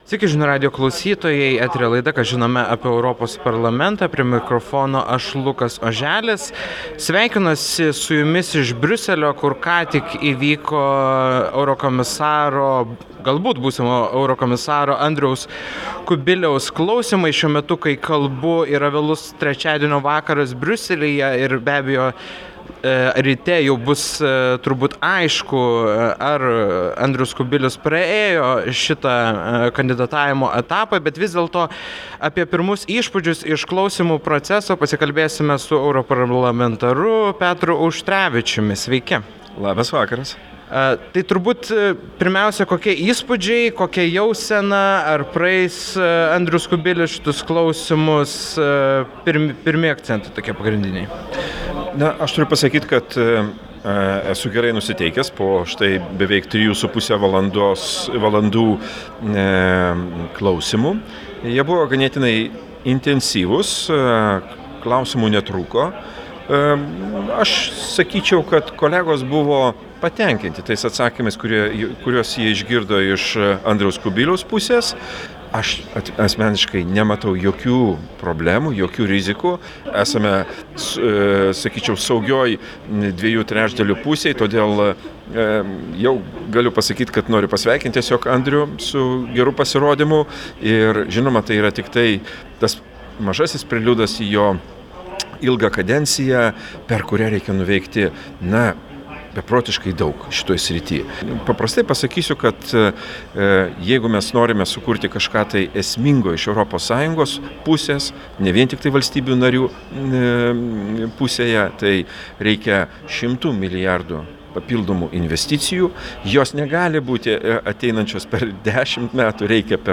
Europarlamentaras Petras Auštrevičius Žinių radijo laidoje „Ką žinome apie Europos Parlamentą?" teigė, kad klausymu metu A. Kubilius pasirodė kaip atsakingas politikas, nes nežadėjo neįvykdomų pažadų.